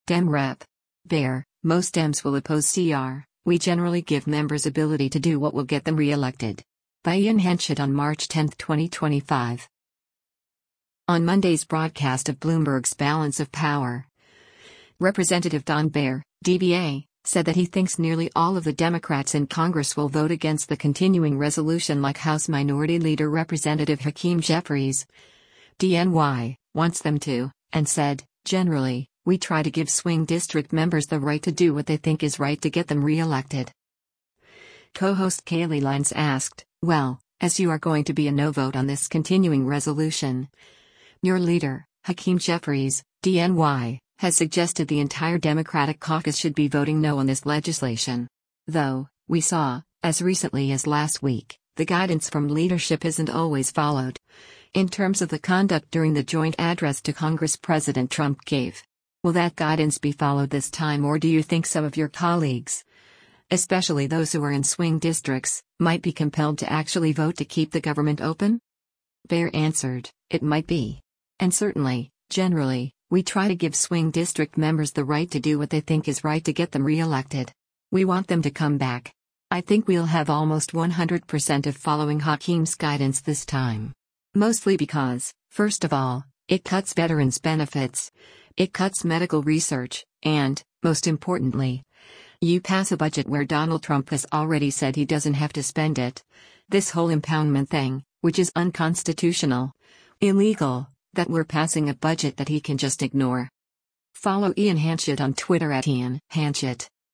On Monday’s broadcast of Bloomberg’s “Balance of Power,” Rep. Don Beyer (D-VA) said that he thinks nearly all of the Democrats in Congress will vote against the continuing resolution like House Minority Leader Rep. Hakeem Jeffries (D-NY) wants them to, and said, “generally, we try to give swing district members the right to do what they think is right to get them re-elected.”